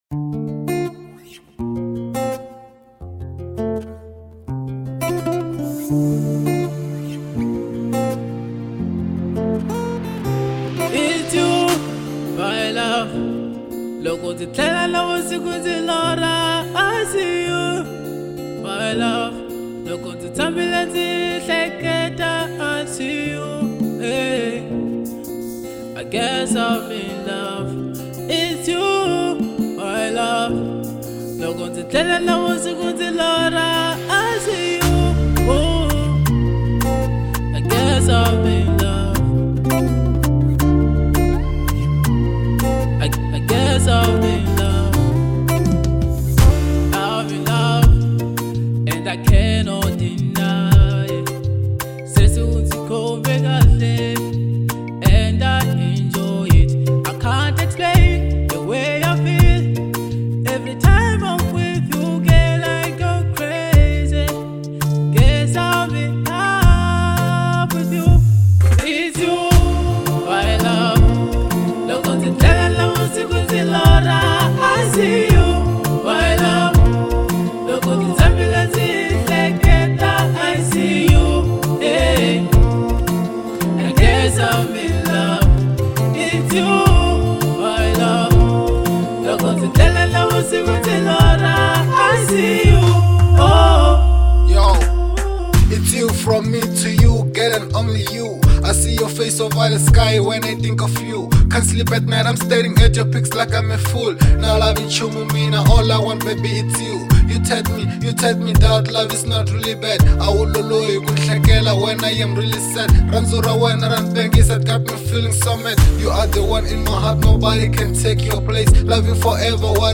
Genre : RnB